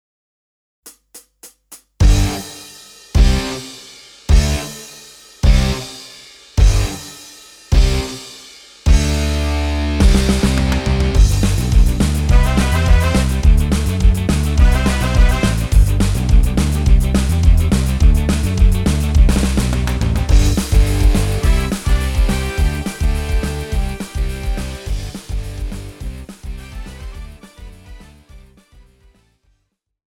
Žánr: Punk
BPM: 210
Key: G
MP3 ukázka